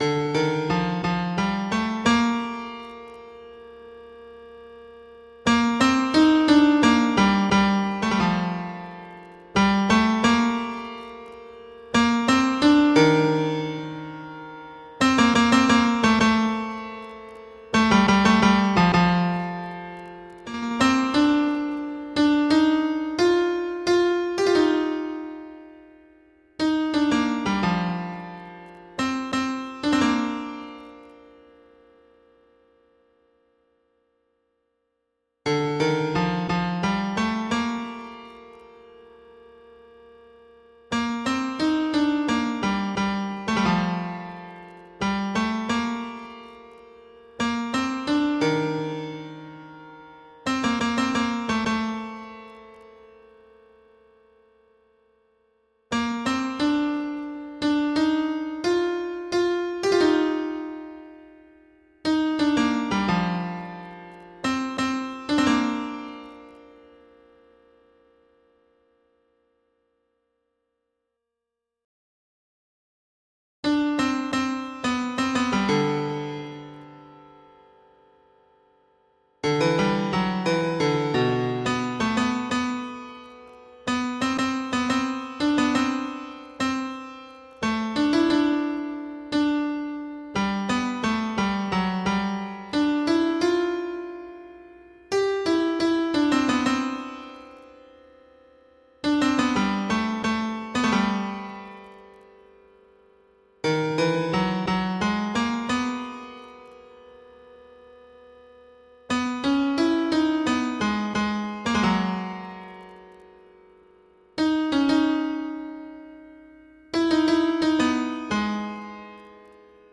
婚禮獻詩檔案
生之頌(女生鋼琴版):
beauty_sopranos.mp3